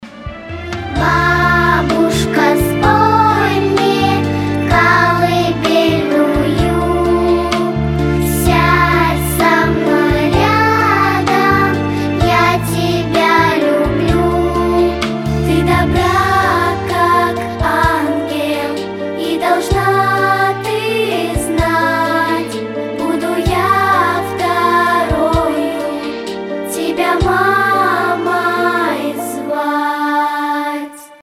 Спокойные рингтоны
Хор , Детский голос